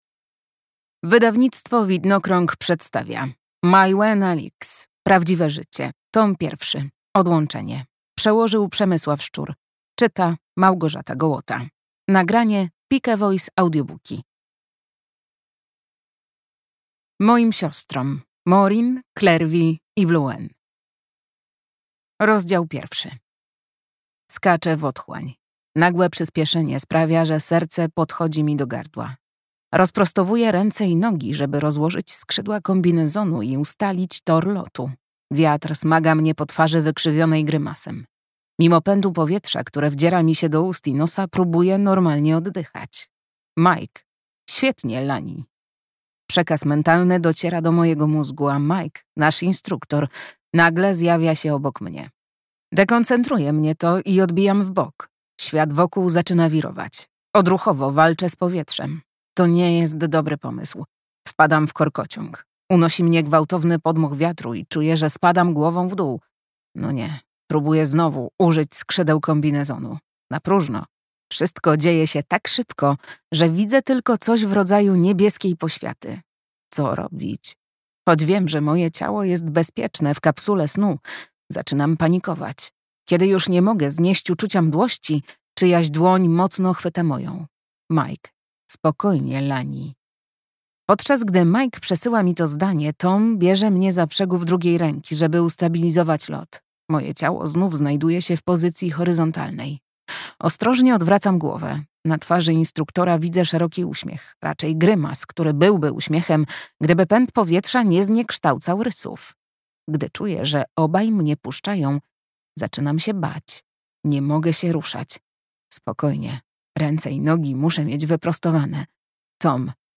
Odłączenie - Maiwenn Alix - audiobook